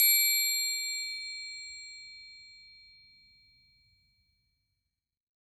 LOTRIANGLE.wav